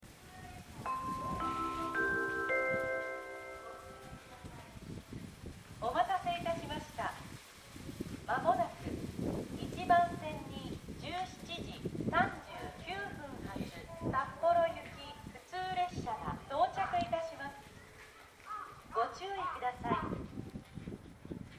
この駅では接近放送が設置されています。
接近放送札幌行き　普通電車　接近放送です。